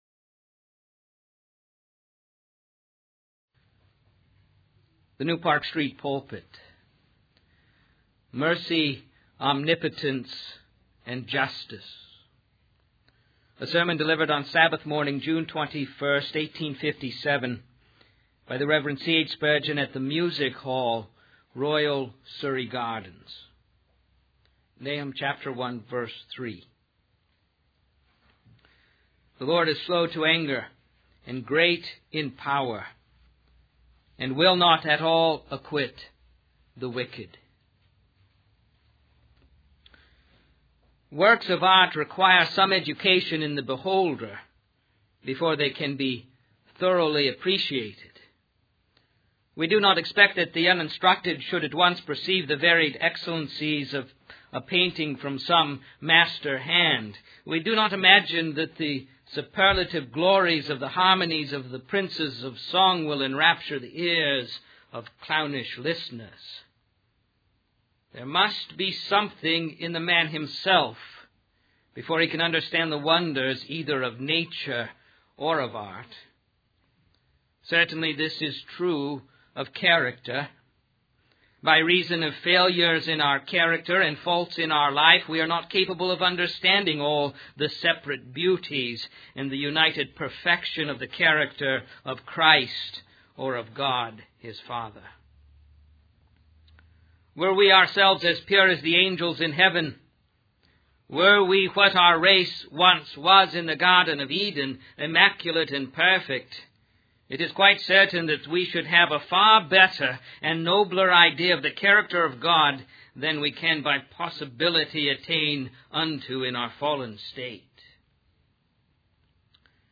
In this sermon, the preacher emphasizes the urgency of recognizing the reality of death and the need for salvation through Christ. He highlights the role of Satan as a destructive force and warns of the consequences of procrastination in seeking God.